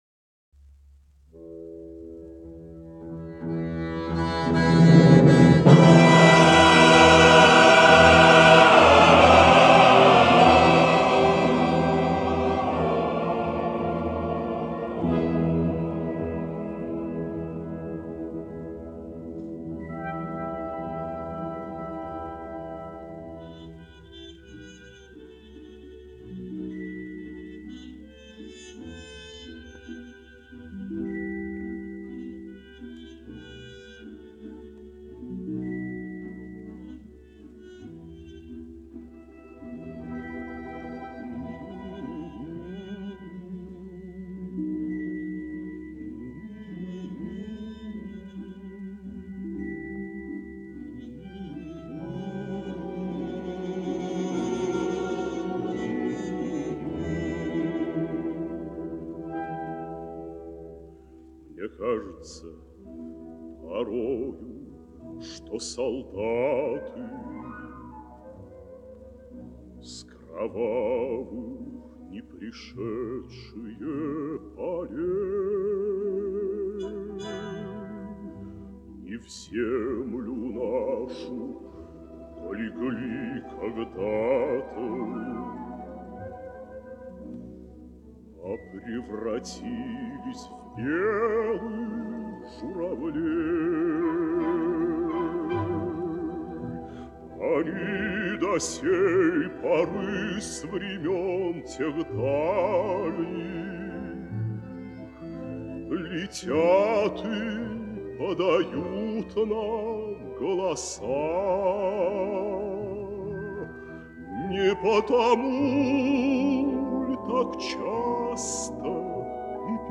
солист